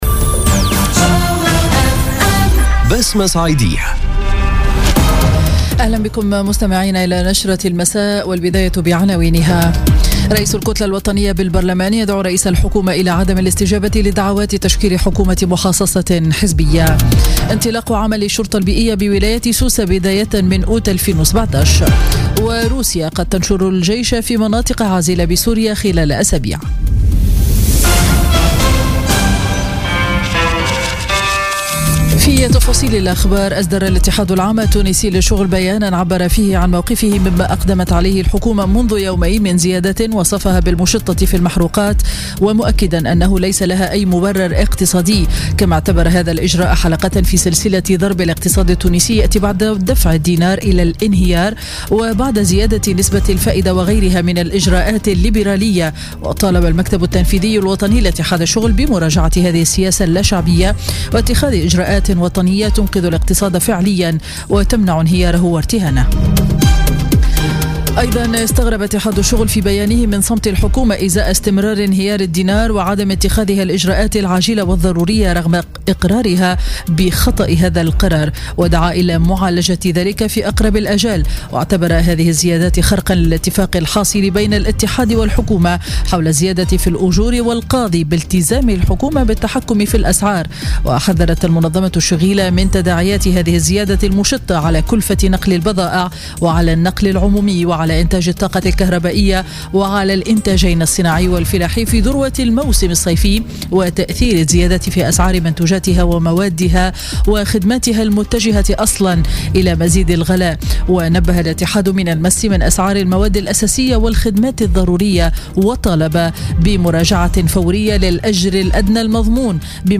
نشرة أخبار السابعة مساء ليوم الثلاثاء 4 جويلية 2017